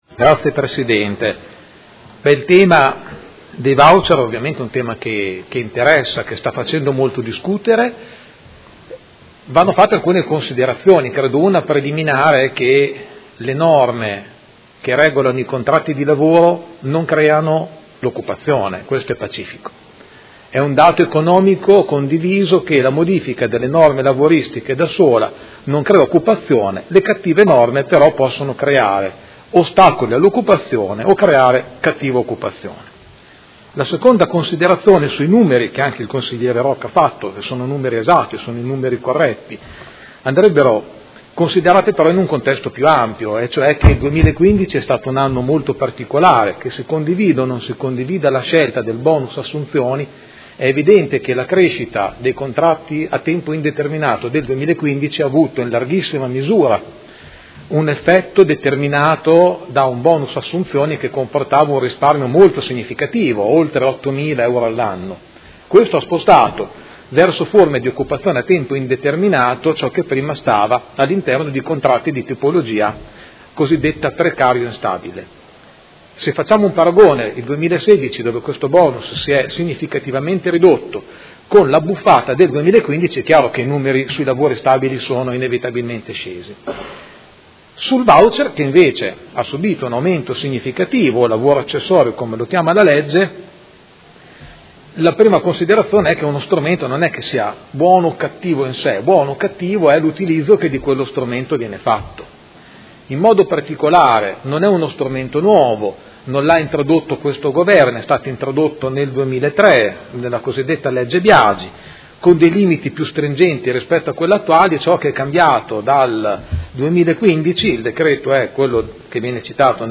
Seduta del 29/09/2016 Dibattito.